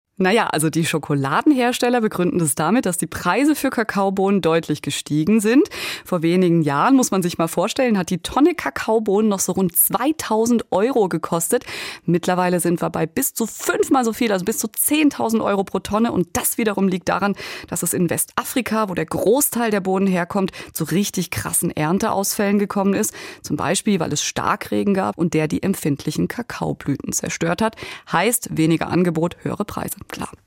Während du dich durchklickst, kannst du auch gern hier mal reinhören – bei SWR3 im Radio haben wir nachgefragt, warum Schokolade zu Ostern eigentlich so teuer ist: